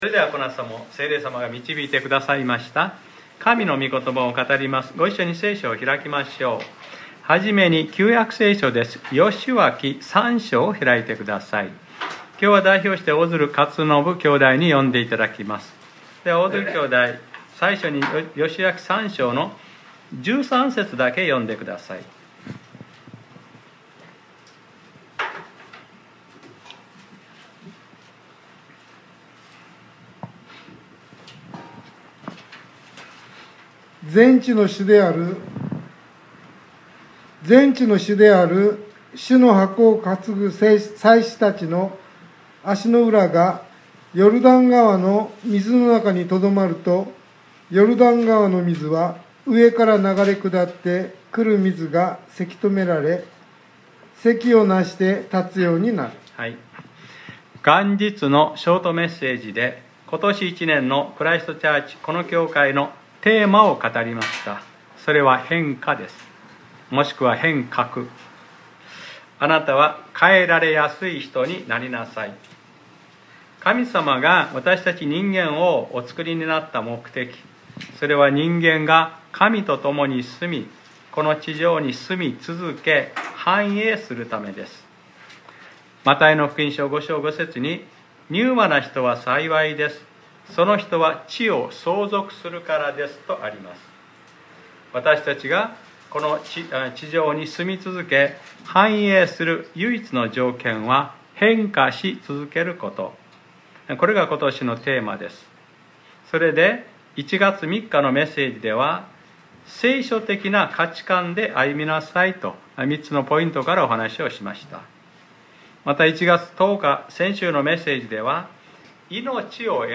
2021年 1月17日（日）礼拝説教『一歩踏み出しなさい』